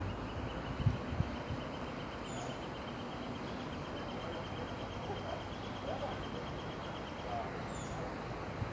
Planalto Woodcreeper (Dendrocolaptes platyrostris)
Province / Department: Rio Grande do Sul
Location or protected area: Gramado
Condition: Wild
Certainty: Observed, Recorded vocal